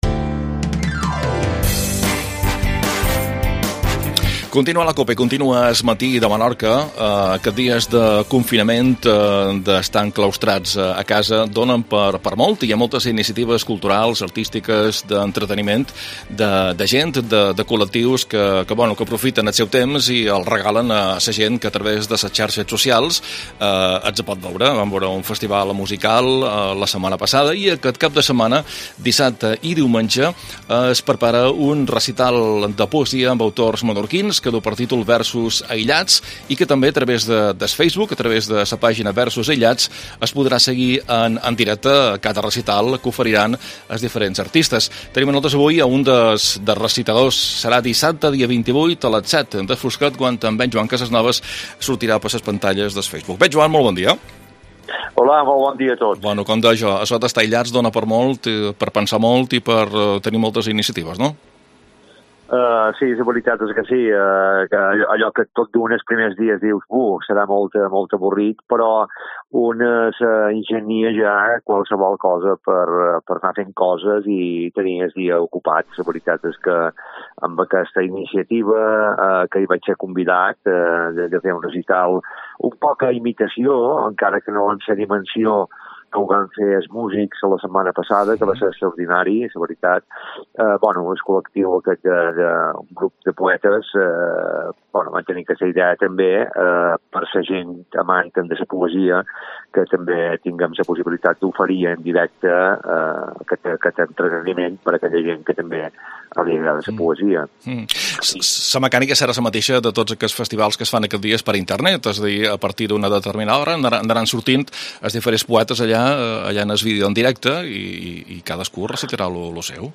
Recital poetic online